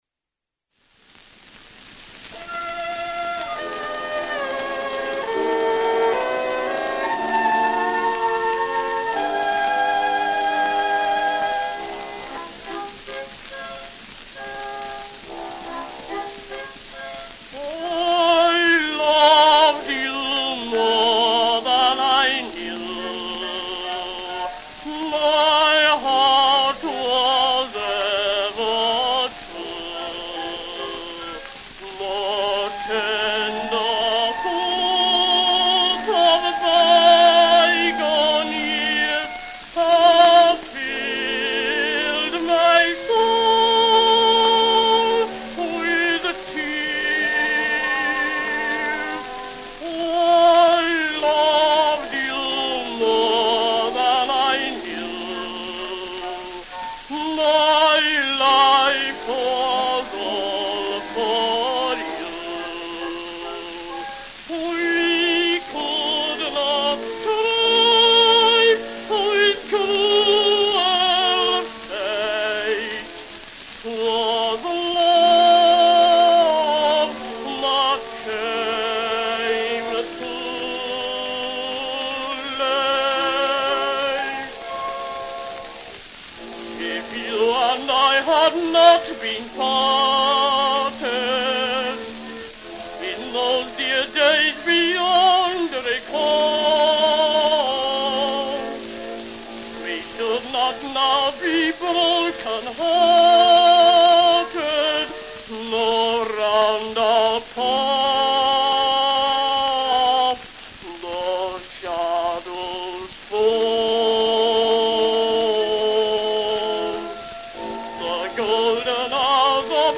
The Phantom Melody - song with orchestra (= I loved you more than I knew)
orchestra
cylinder